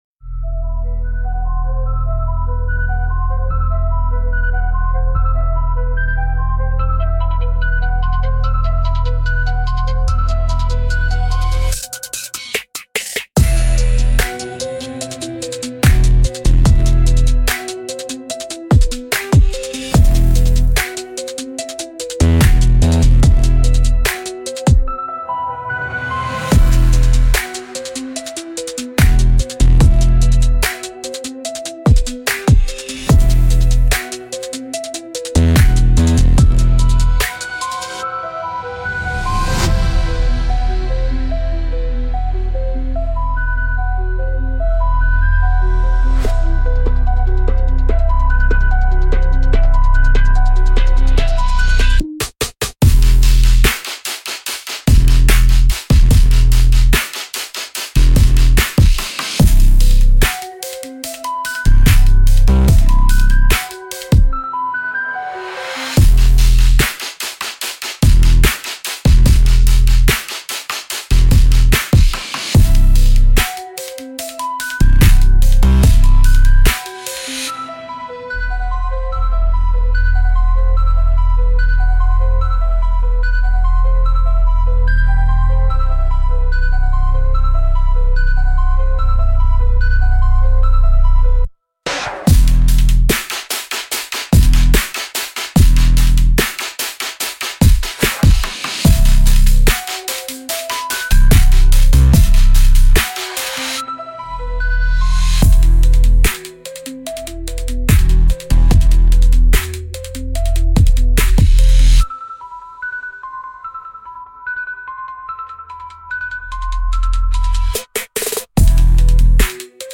Instrumental - Glitch in the Swamp Matrix